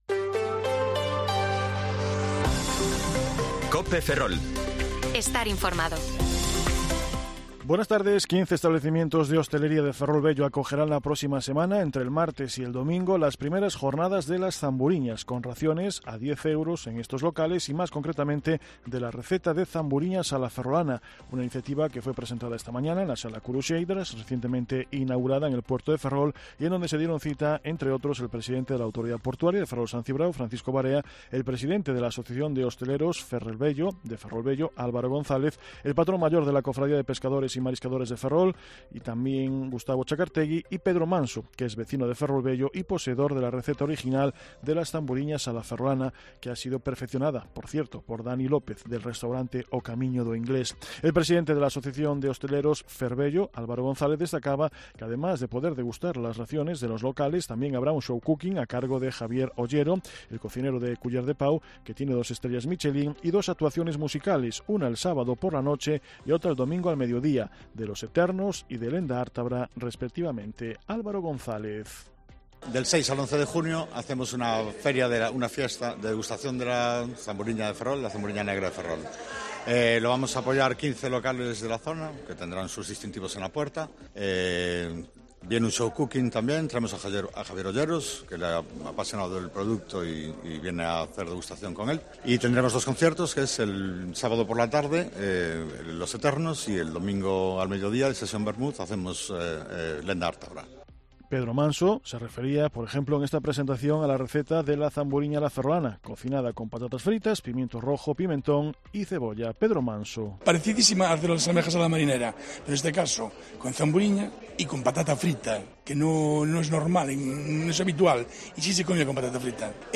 Informativo Mediodía COPE Ferrol 2/6/2023 (De 14,20 a 14,30 horas)